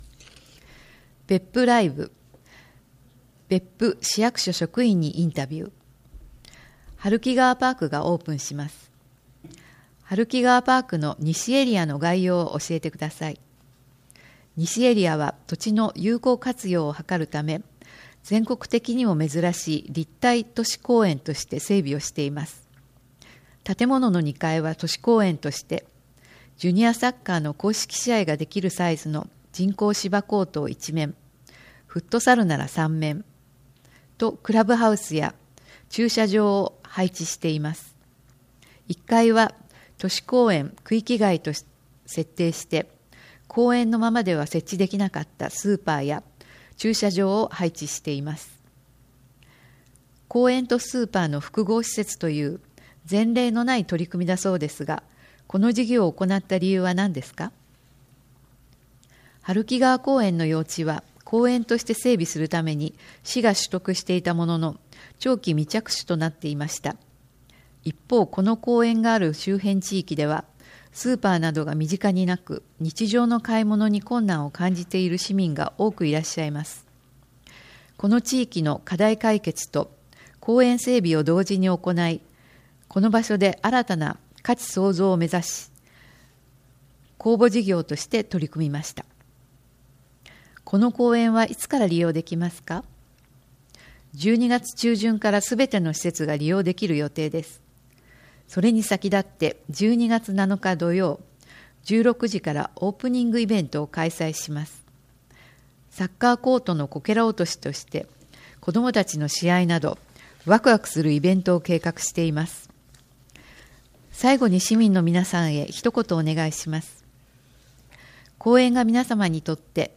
毎月市報べっぷの中から、視覚に障がいがある皆さんに特にお知らせしたい記事などを取り上げ、ボランティアグループ「わたげの会」の皆さんに朗読していただいて作られています。